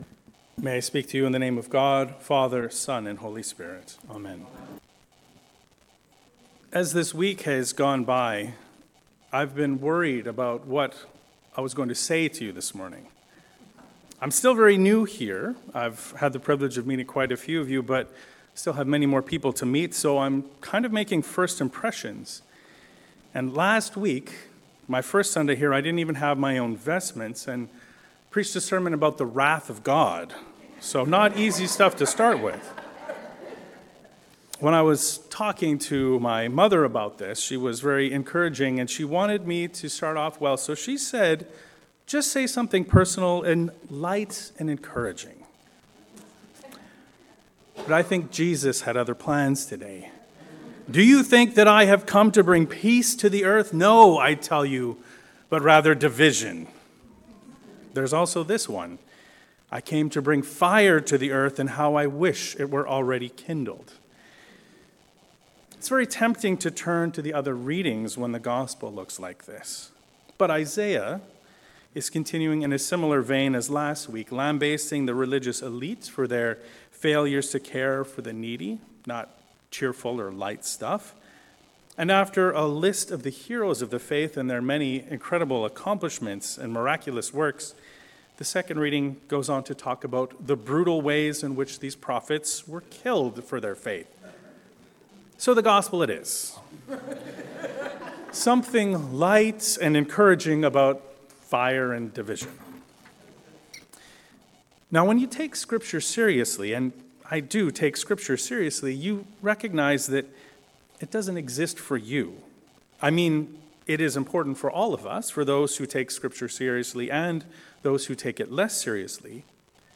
Turning Flax into Gold. A sermon on Luke 12